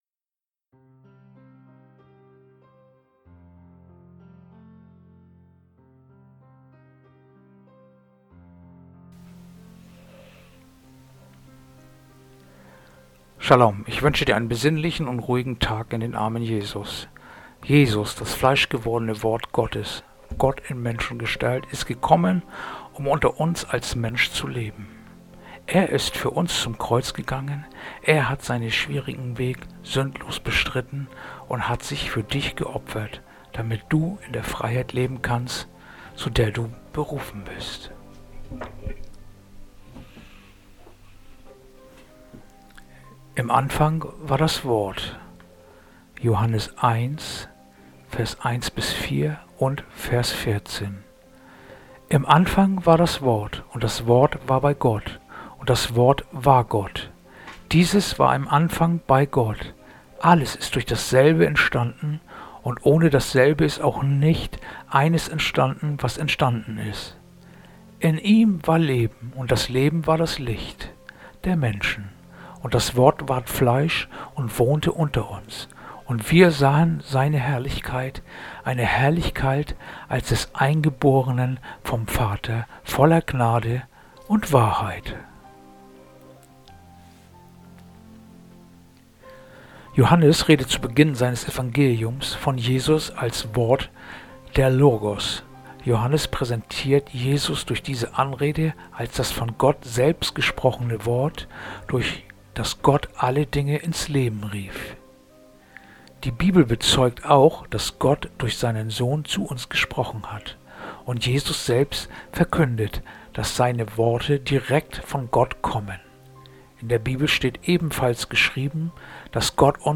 Andacht-vom-30-Dezember-Johannes-1-1-4.-14